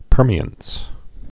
(pûrmē-əns)